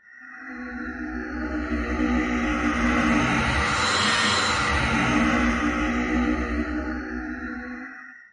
标签： 惊悚 响亮 恐怖 令人毛骨悚然 恐怖 怪物 可怕
声道立体声